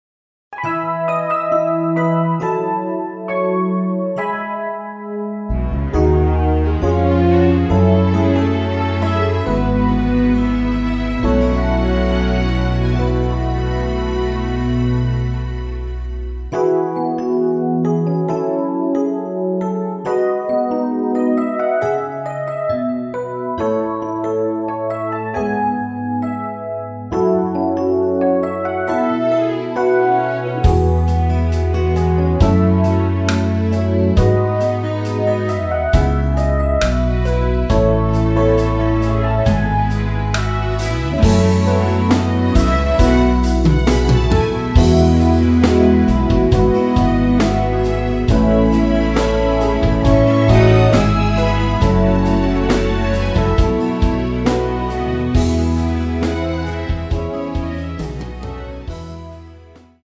올린 MR 입니다.
◈ 곡명 옆 (-1)은 반음 내림, (+1)은 반음 올림 입니다.
앞부분30초, 뒷부분30초씩 편집해서 올려 드리고 있습니다.